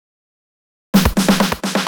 Fill 128 BPM (1).wav